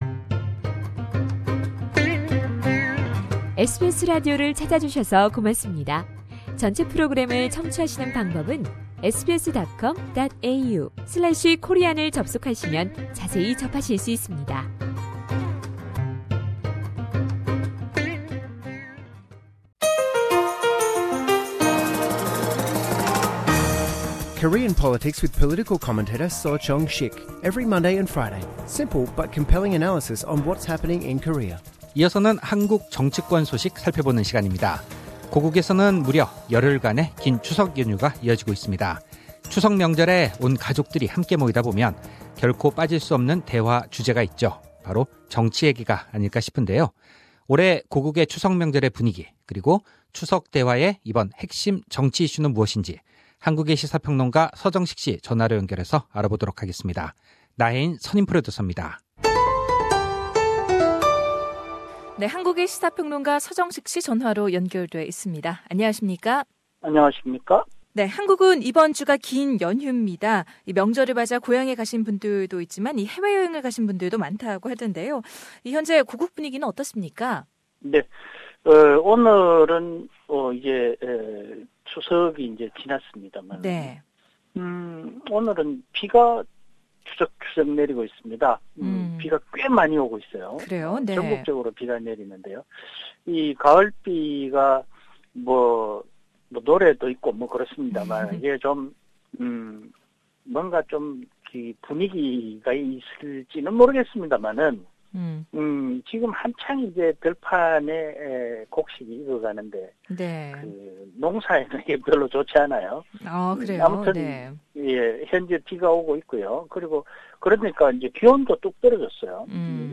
올 고국 추석의 자세한 명절의 분위기 그리고 추석 대화의 핵심 정치 이슈 알아봅니다. 전체 인터뷰는 상단의 팟캐스트를 통해 확인하실 수 있습니다.